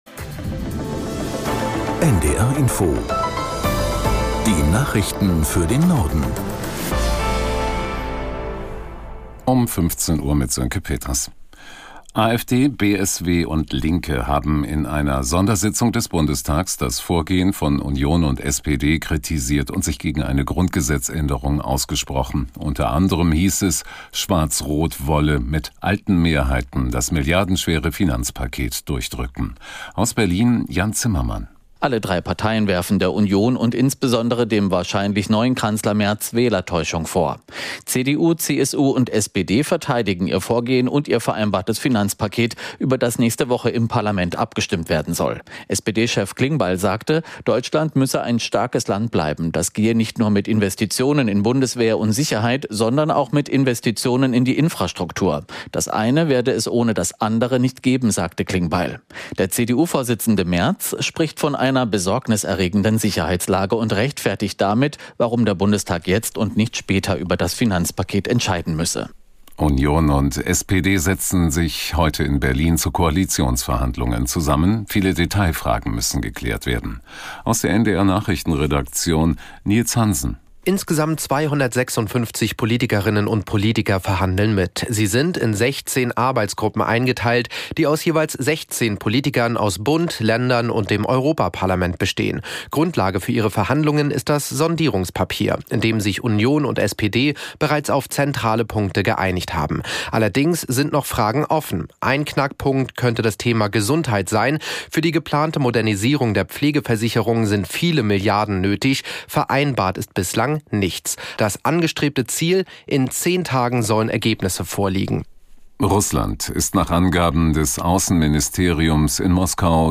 Nachrichten - 13.03.2025